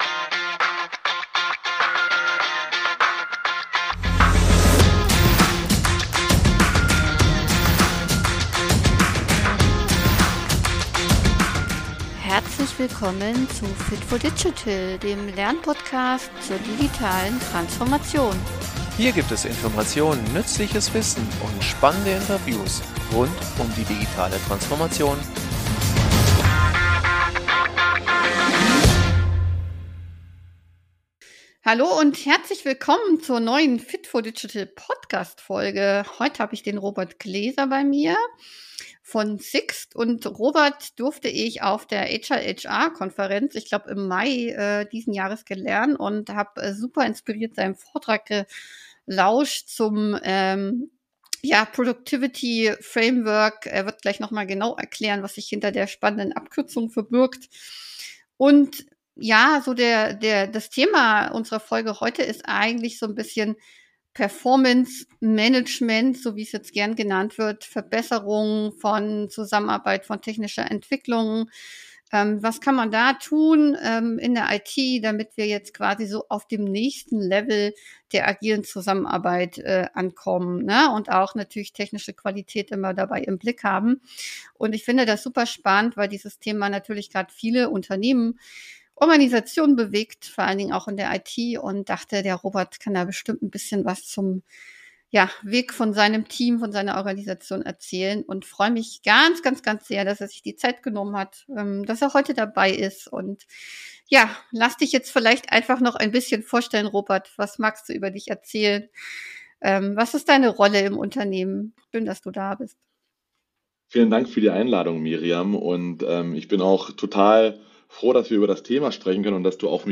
Agiles Performance Management - Interview